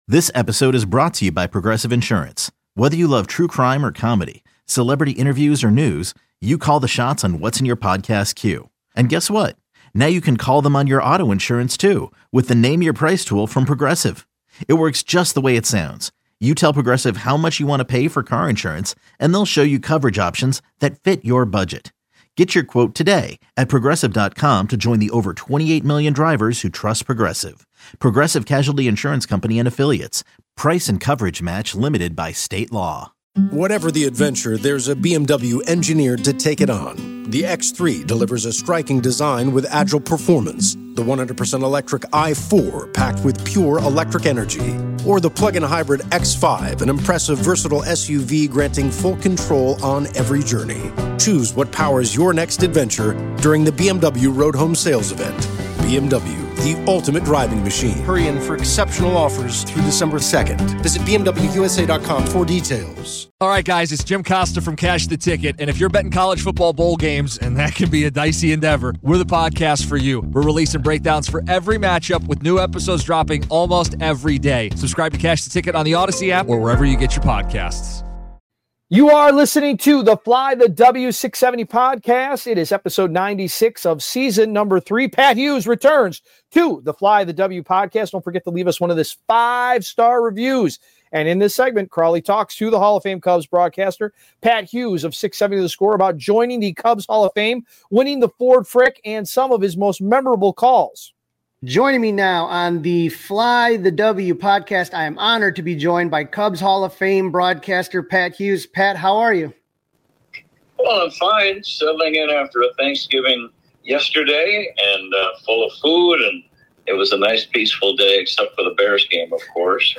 Recurring guests include Bears linebacker T.J. Edwards, Pro Football Talk founder Mike Florio, Cubs outfielder Ian Happ and Cubs president of baseball operations Jed Hoyer.
Catch the show live Monday through Friday (10 a.m.- 2 p.m. CT) on 670 The Score, the exclusive audio home of the Cubs and the Bulls, or on the Audacy app.